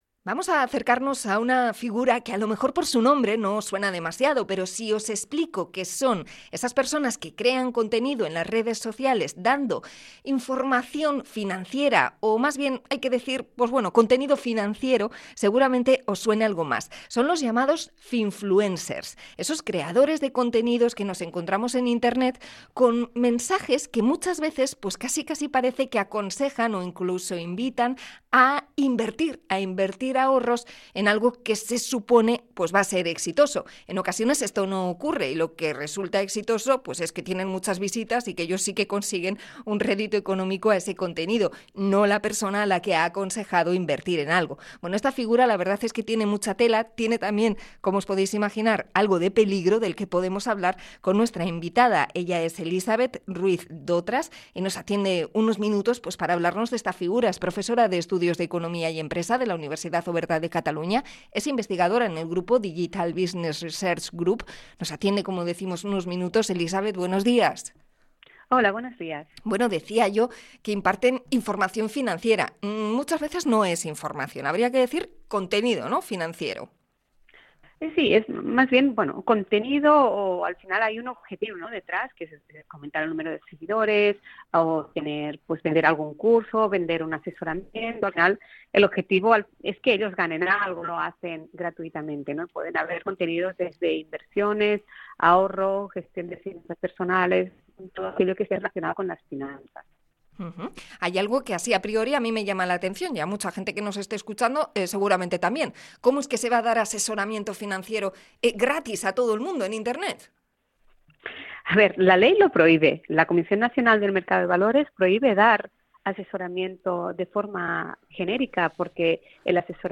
Entrevista a la experta de la UOC sobre los llamados asesores fiscales o 'finfluencers'